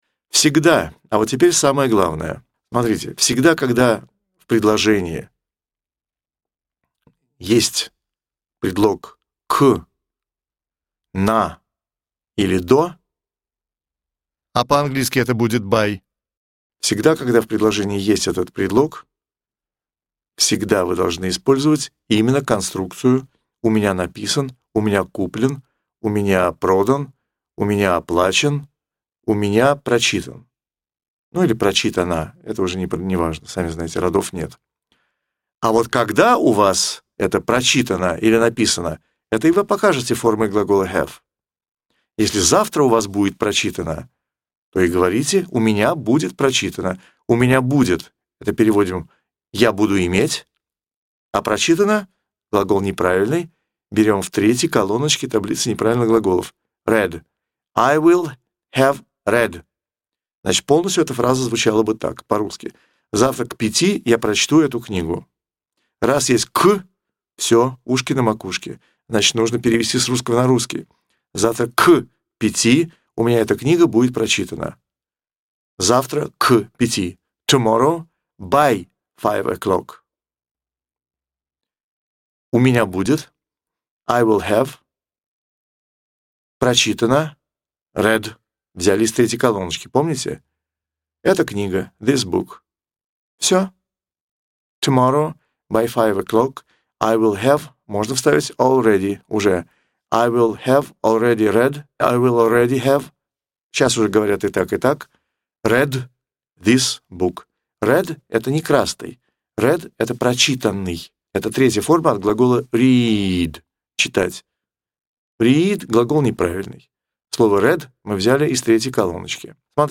Аудиокурс английского языка